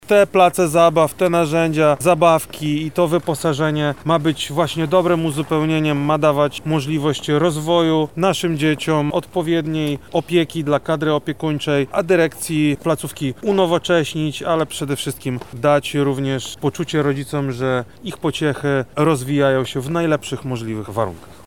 Krzysztof Komorski – mówi wojewoda lubelski, Krzysztof Komorski.